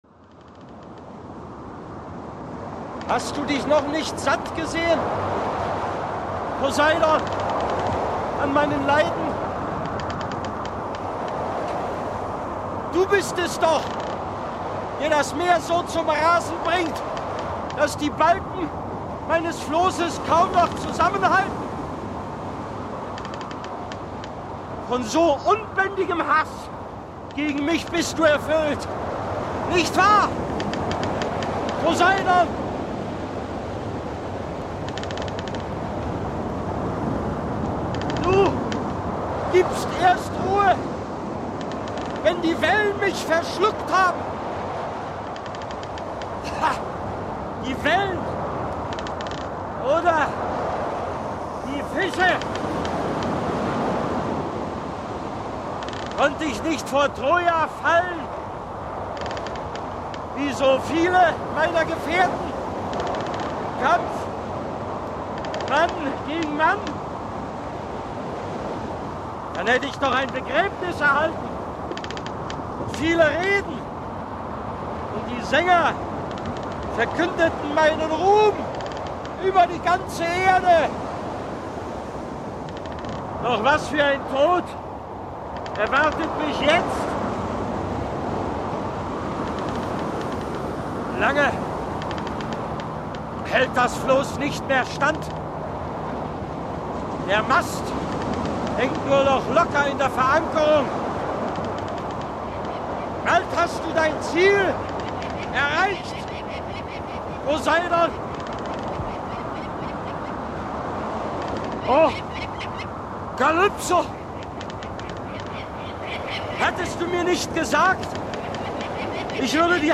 Hörspiel (4 CDs)
Michael Degen (Sprecher)
Die Schauspielerlegende Michael Degen alias Odysseus, Sieger von Troja und Bezwinger von Riesen und Zauberinnen, nimmt Kurs auf Ithaka.